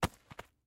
Звуки кошелька
упал на землю